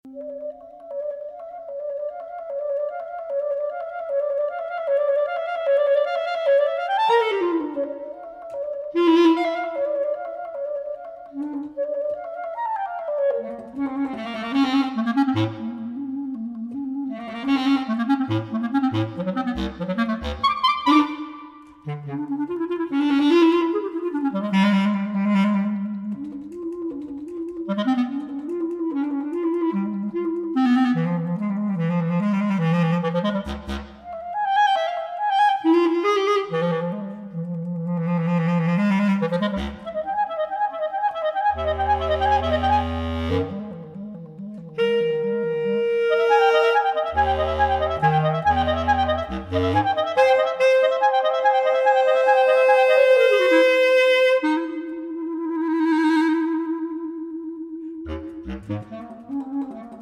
Clarinet
Bass Clarinet